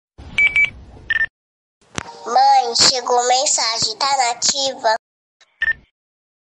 rádio de notificação🥋🔥 sound effects free download